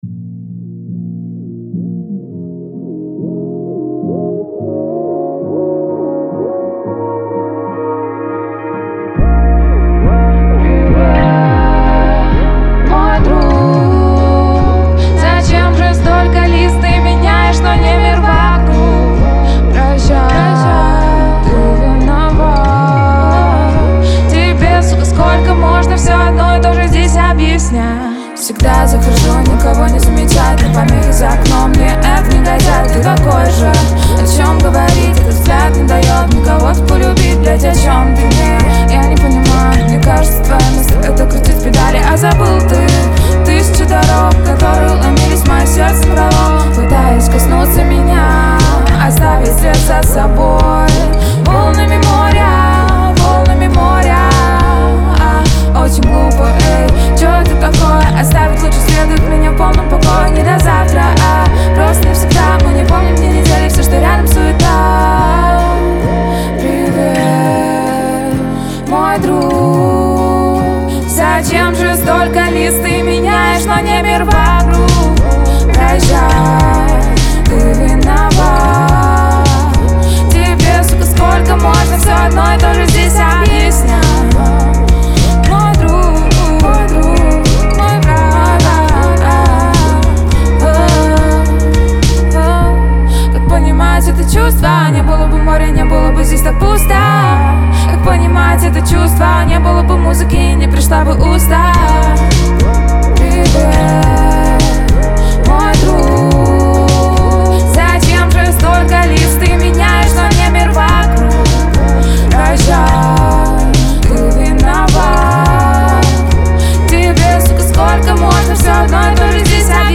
Настроение трека – меланхоличное, но с нотами надежды.